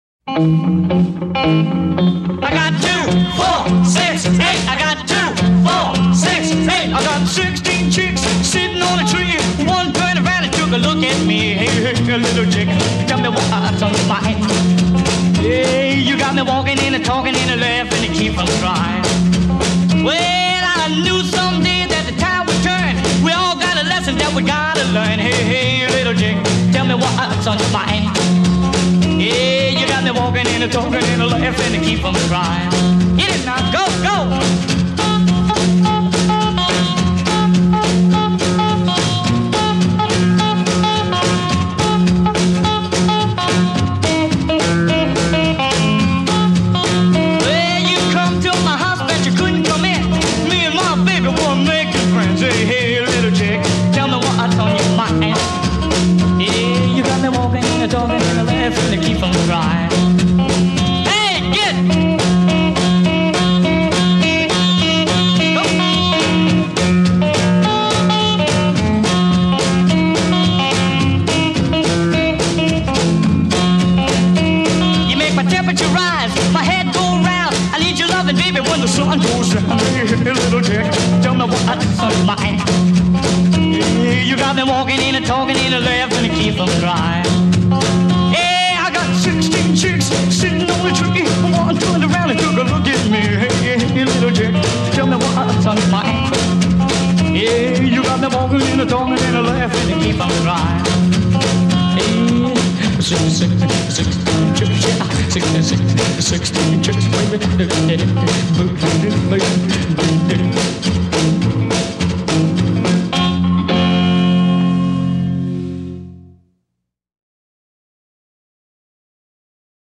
Hard Hitting Rockabilly Classics